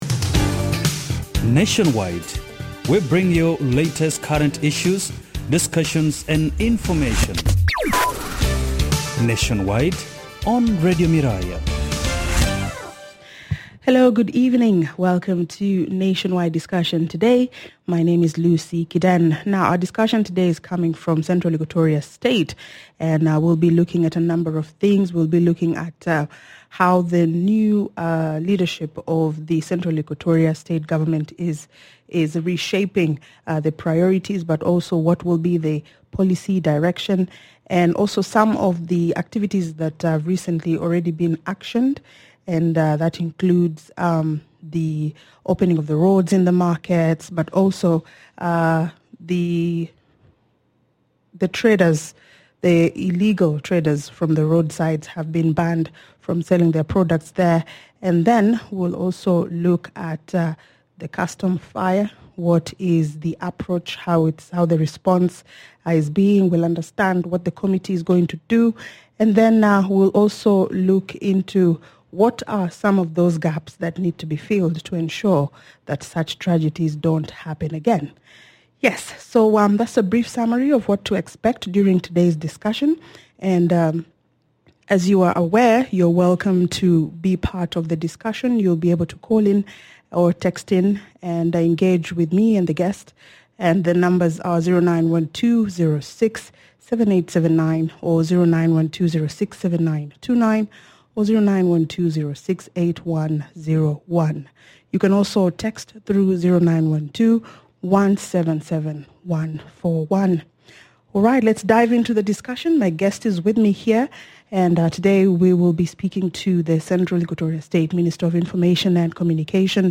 Hon Patrick Nyarsuk, CES Minister of Information and Communication